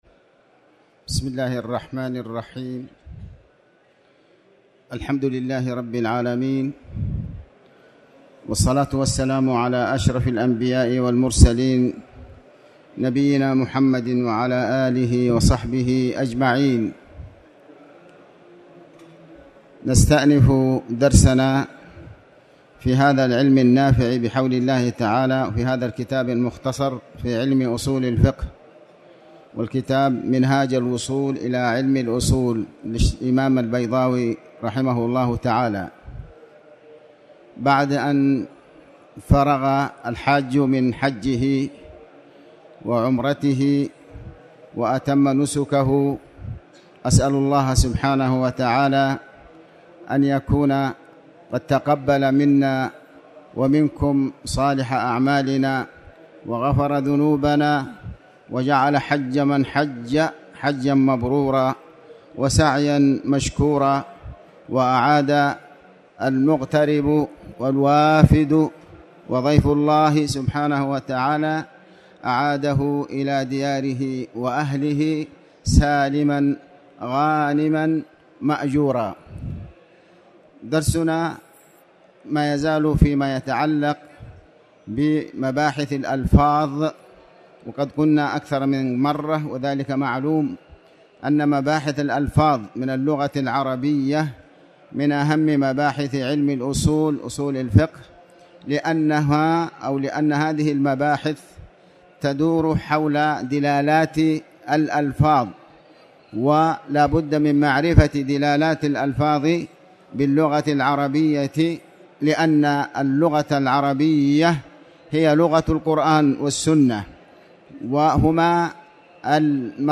تاريخ النشر ٢٥ ذو الحجة ١٤٣٩ هـ المكان: المسجد الحرام الشيخ: علي بن عباس الحكمي علي بن عباس الحكمي مباحث الألفاظ